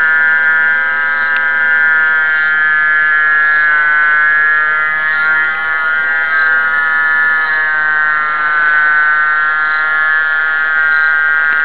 sound of a cicada buzzing so loudly outside that i slept with earplugs.
cicada_at_night.wav